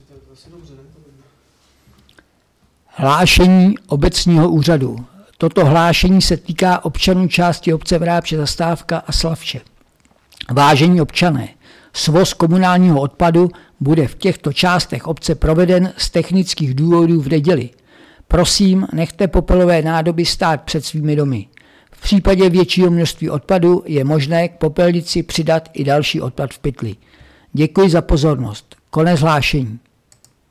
14.06.2024Hlášení rozhlasu